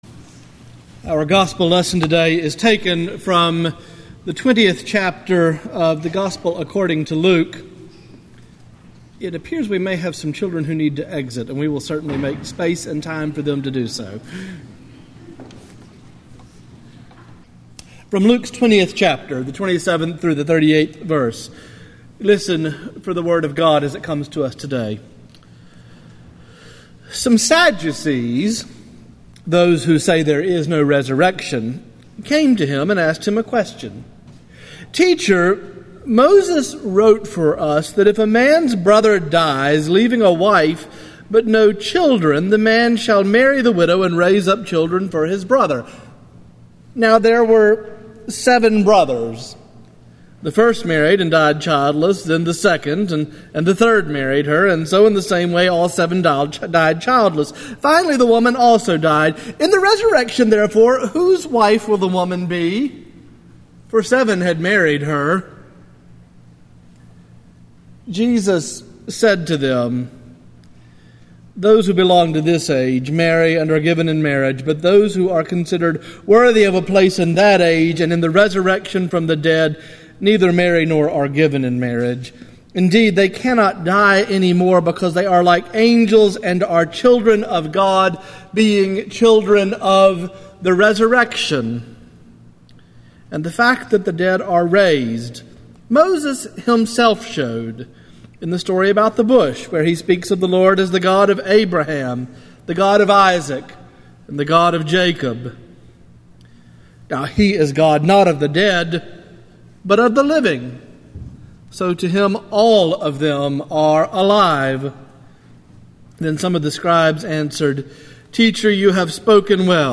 THE MEDITATION